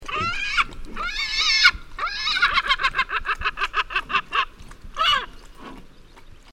Gulls in Korgalzyn reserve, Akmola area, Kazakstan
Two examples of cachinnans-like calls:
Call 1 (63 KB)